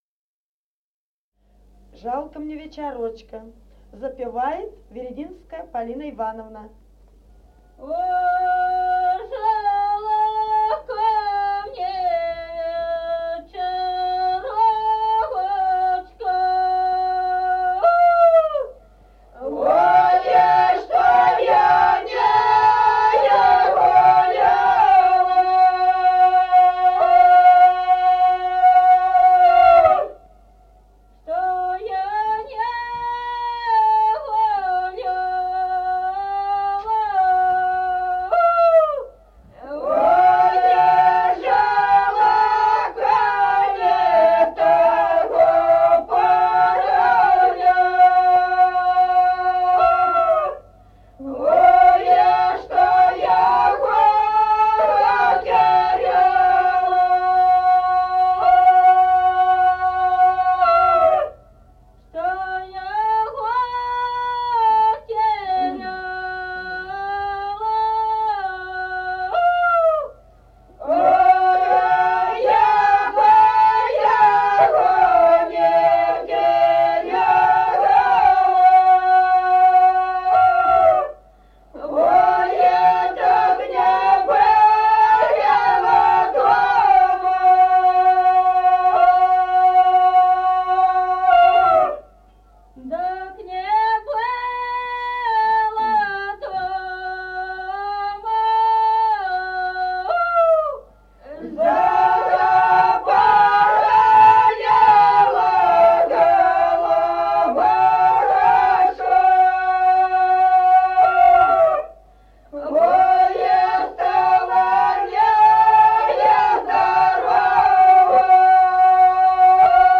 Народные песни Стародубского района «Ох, жалко мне вечерочка», жнивная.